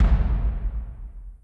OnSkipButton.wav